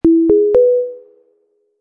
intercom.mp3